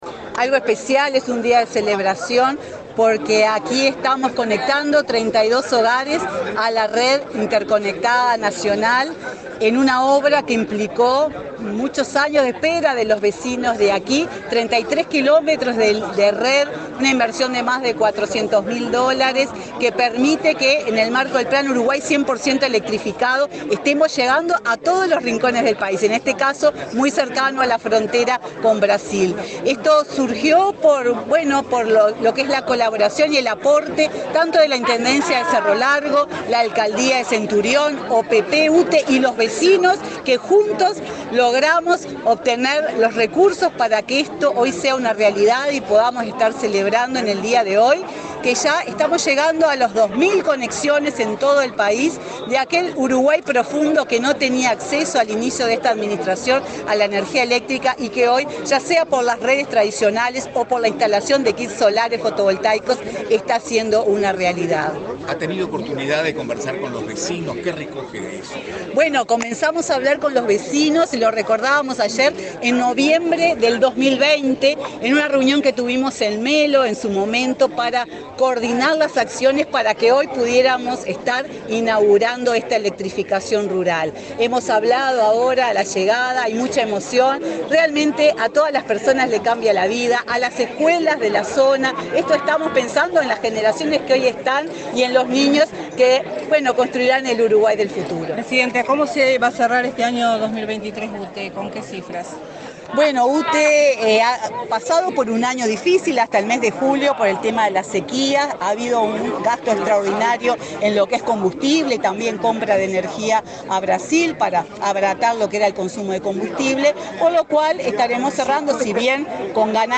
Declaraciones de la presidenta de UTE, Silvia Emaldi
Este viernes 1.°, el presidenta de UTE, Silvia Emaldi, dialogó con la prensa en Cerro Largo, antes de participar del acto de inauguración de obras de